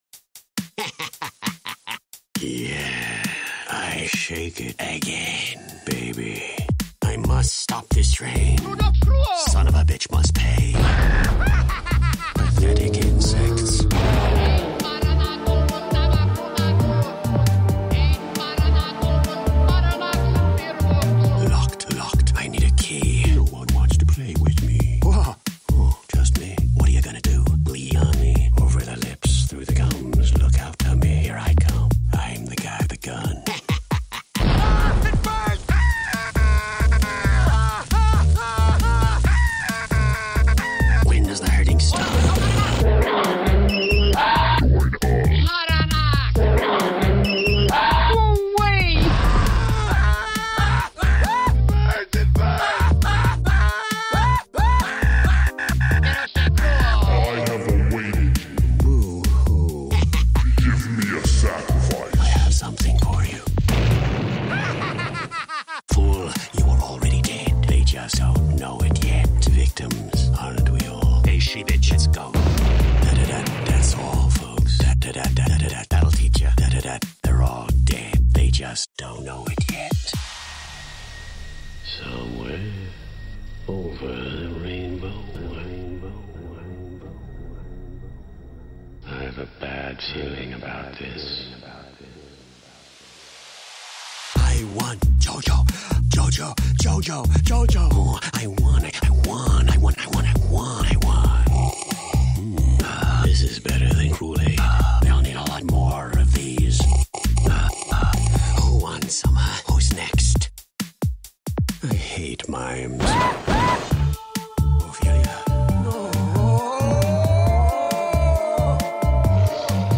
Für die Kiffer unter euch.